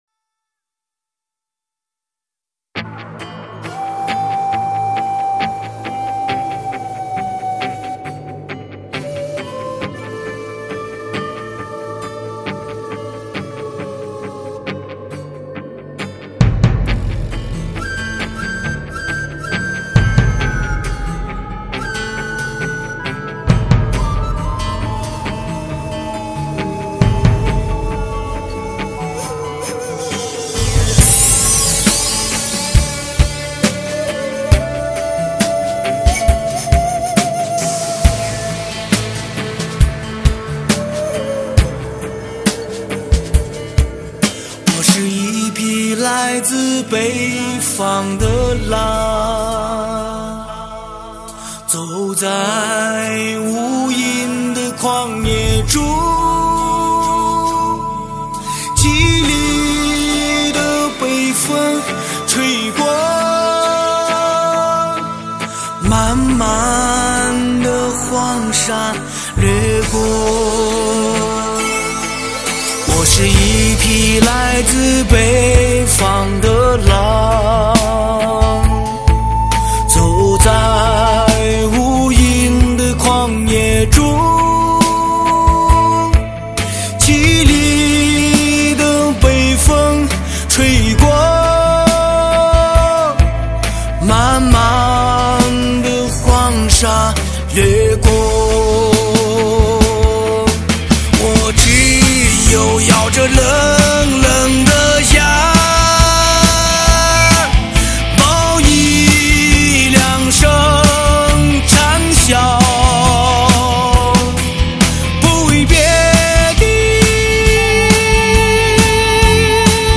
感情丰富的演唱极具撼人的感染力！极品流行发烧人声测试